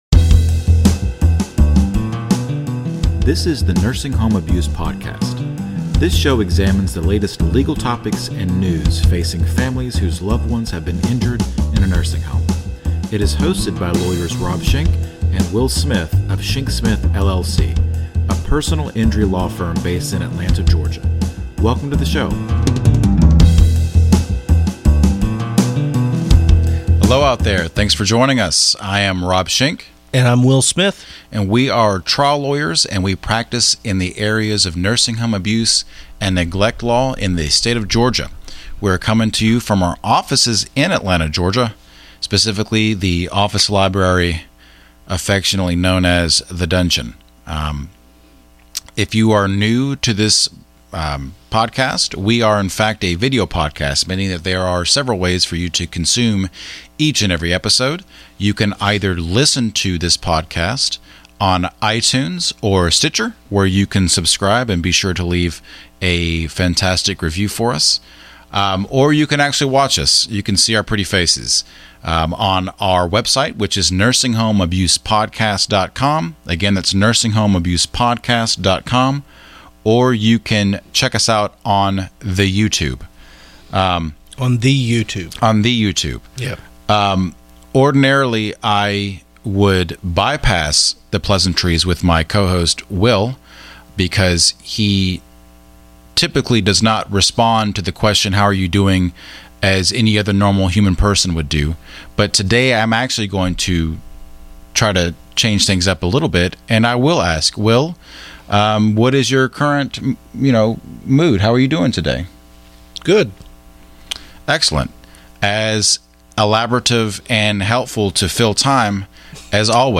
This is the Nursing Home Abuse Podcast. This show examines the latest legal topics and news facing families whose loved ones have been injured in a nursing home.